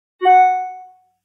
Звук полученного письма на электронный ящик, мелодии сообщений и уведомлений в mp3